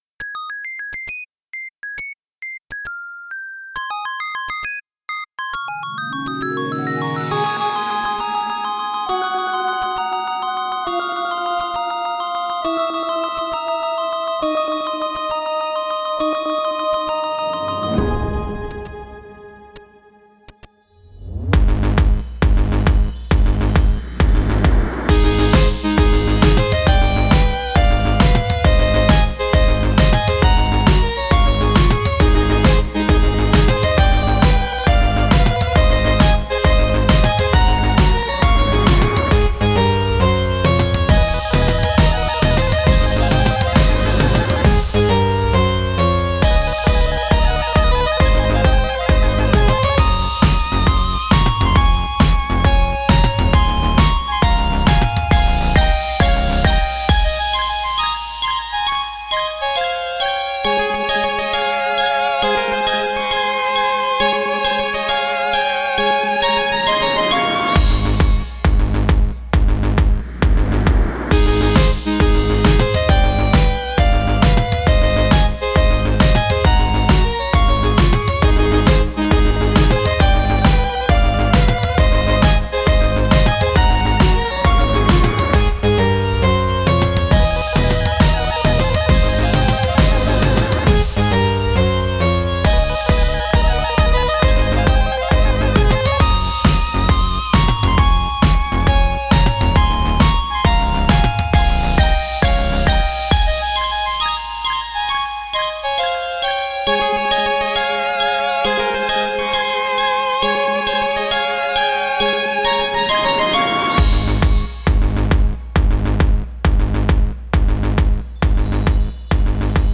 NES music Remix